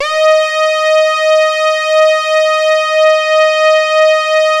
75-TARKUS D#.wav